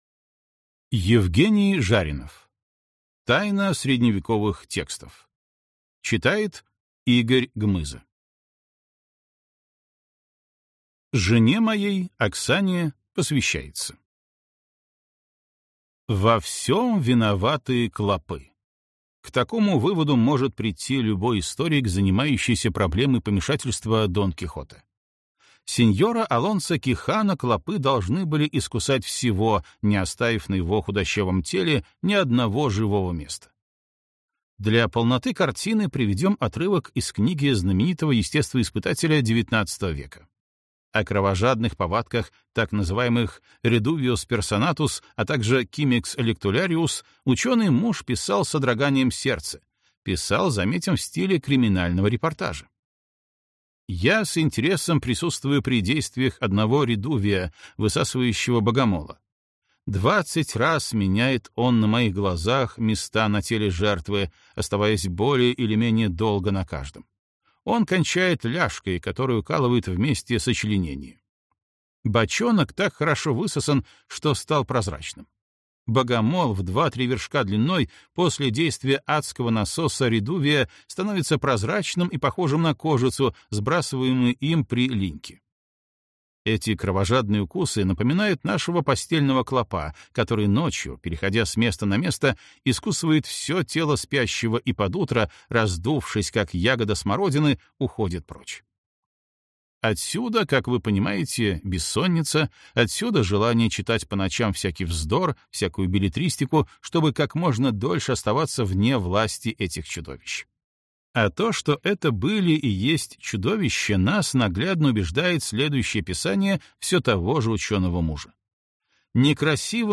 Аудиокнига Тайна cредневековых текстов. Библиотека Дон Кихота | Библиотека аудиокниг